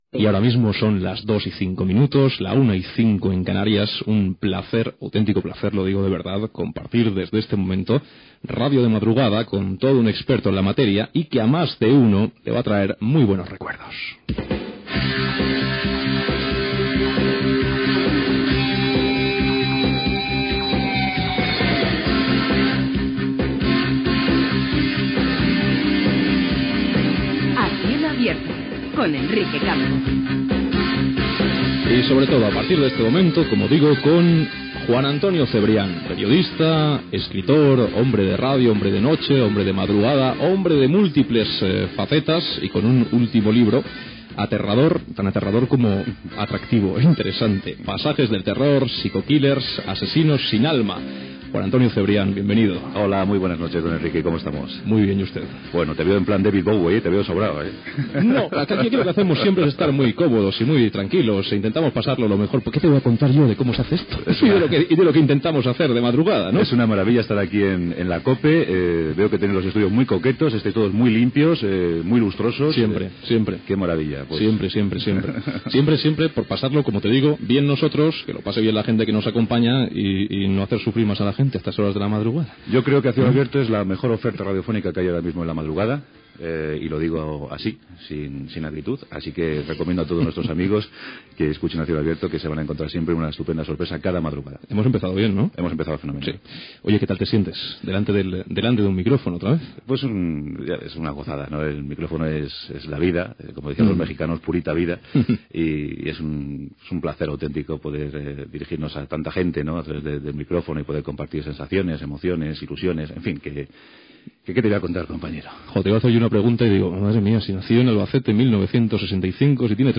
Entrevista al comunicador Juan Antonio Cebrián, per la publicació del llibre "Pasajes del terror".
Entreteniment